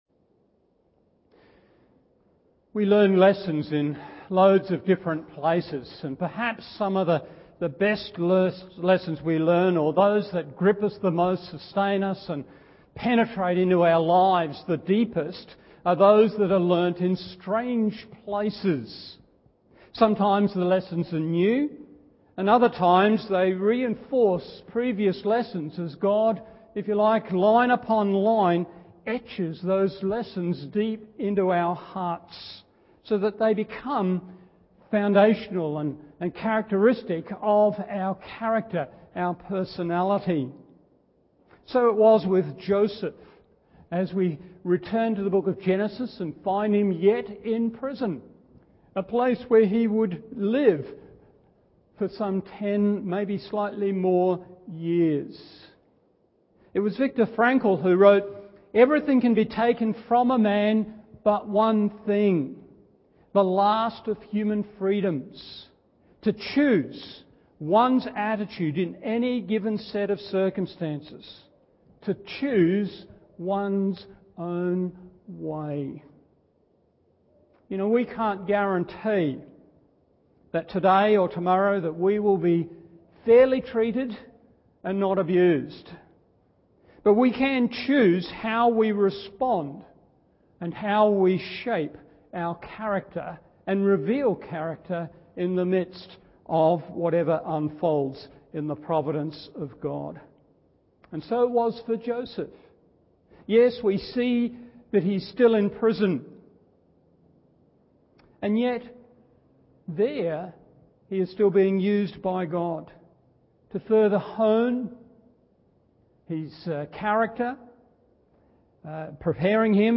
Morning Service Genesis 40 1. Focused on God’s Glory 2. Faithful with God’s Word 3. Fortified in God’s Faithfulness…